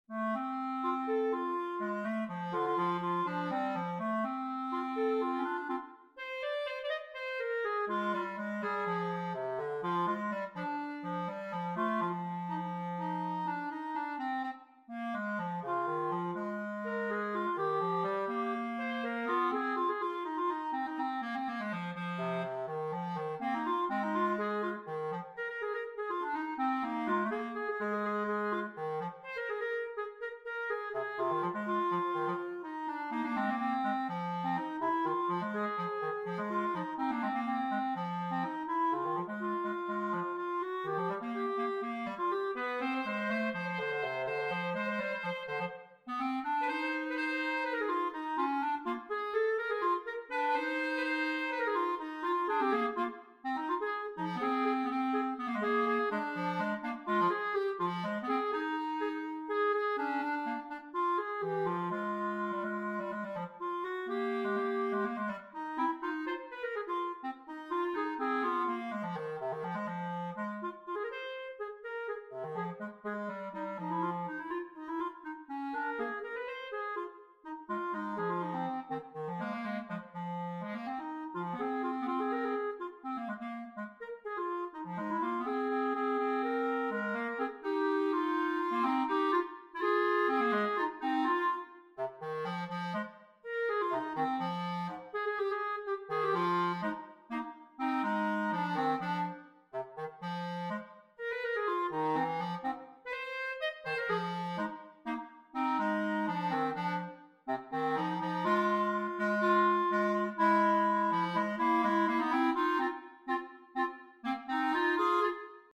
Gattung: Für 2 Klarinetten
Besetzung: Instrumentalnoten für Klarinette